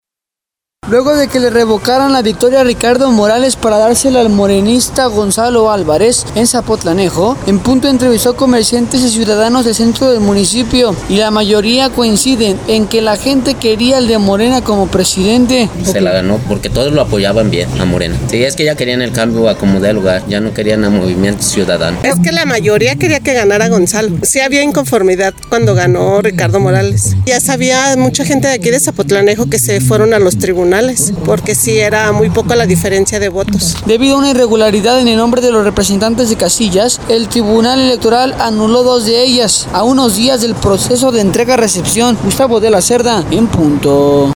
Luego de que le revocaran la victoria a Ricardo Morales para dársela al Morenista Gonzálo Álvarez en Zapotlanejo, En Punto entrevistó a comerciantes y ciudadanos del centro del municipio, y la mayoría coincide en que que la gente quería al de Morena como presidente.